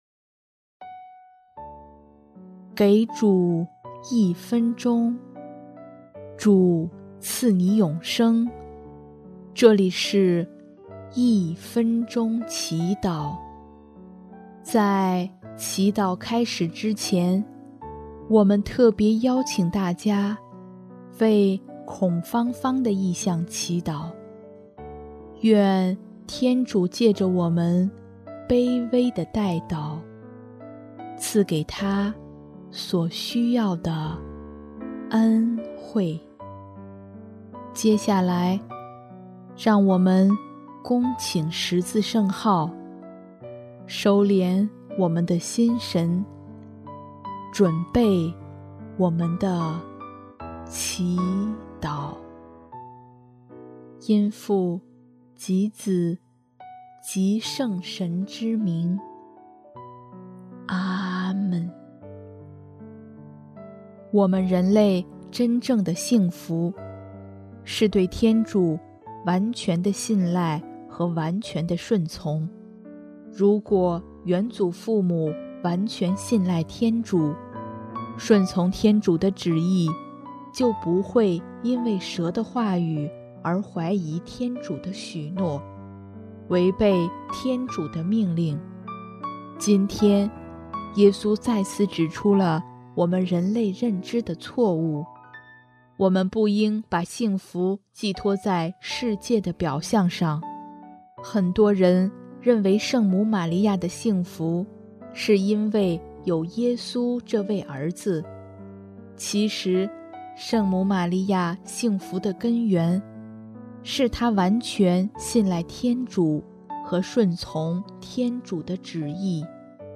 音乐： 主日赞歌《蒙福者》